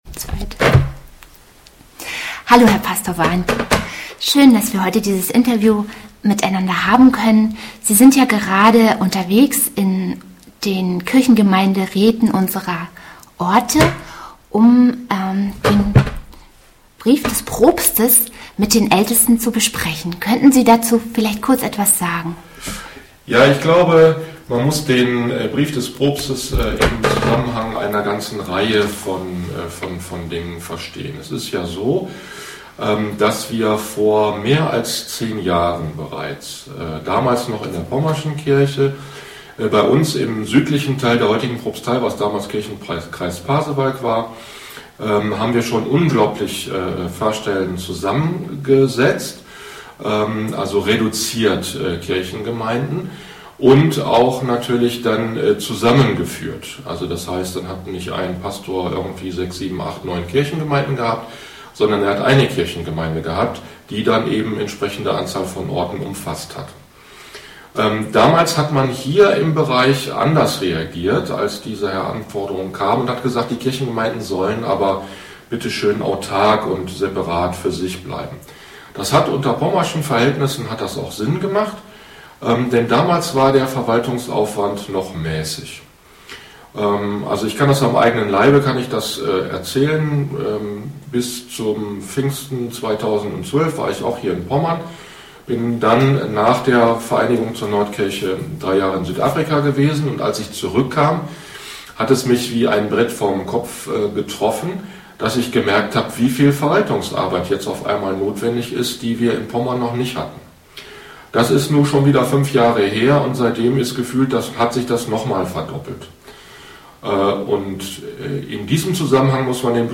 der Zusammenschluß unserer Kirchengemeinden wird seit einiger Zeit diskutiert. Nachstehend hören Sie ein Interview zum Thema.